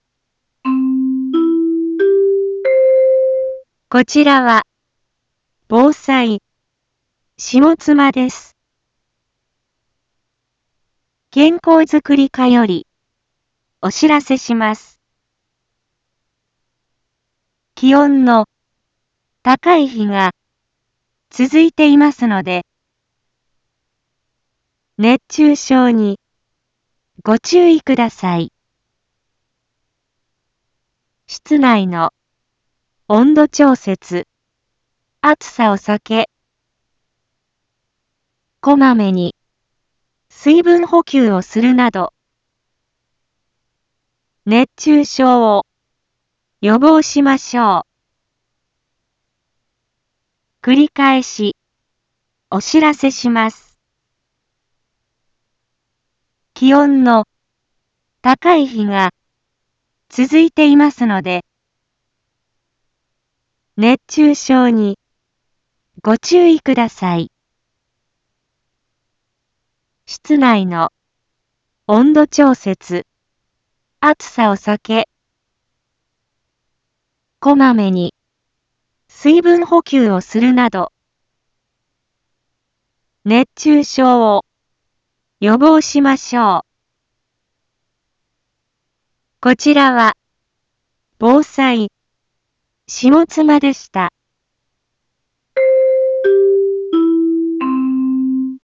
Back Home 一般放送情報 音声放送 再生 一般放送情報 登録日時：2023-07-24 11:01:42 タイトル：熱中症注意のお知らせ インフォメーション：こちらは、防災、下妻です。